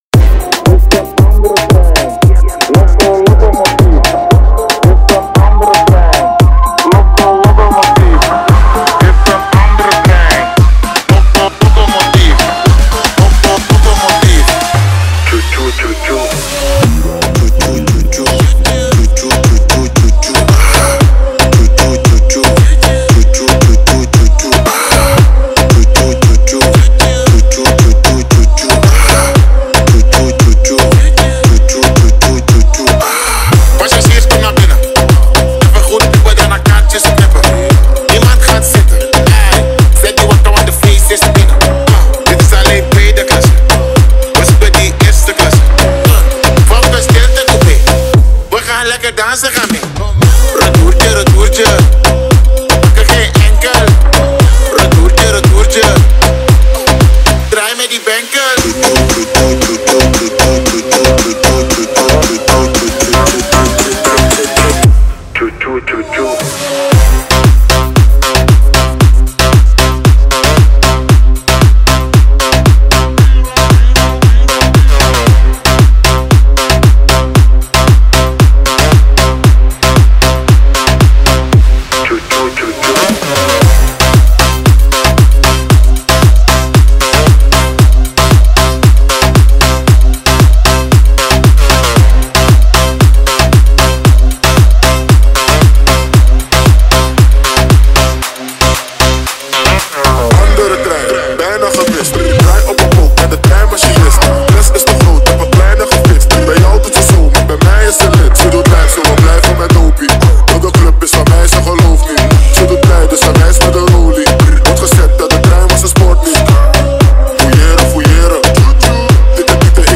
Download Remix House for the track